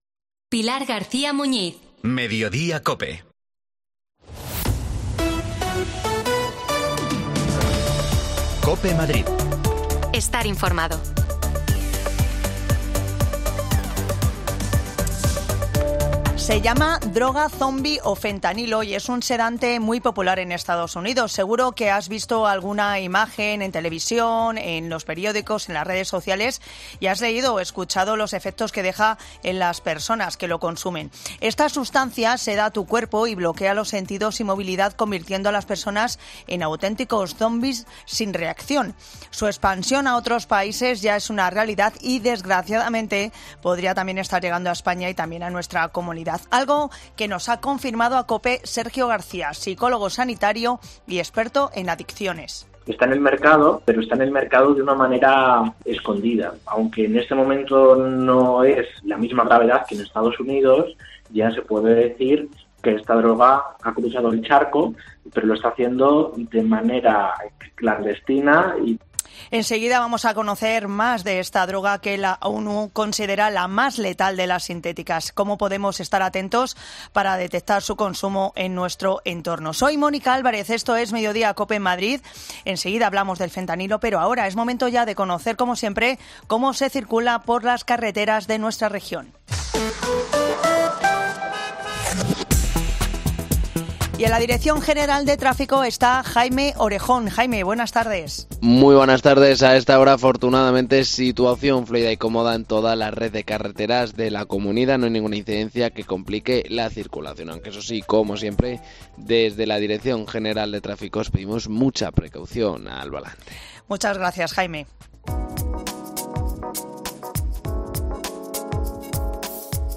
AUDIO: El Fentanilo o 'Droga Zombie' comienza a hacerse hueco en Madrid... Una experta del FAD Juventud nos habla sobre ello, nos cuenta como actúa y...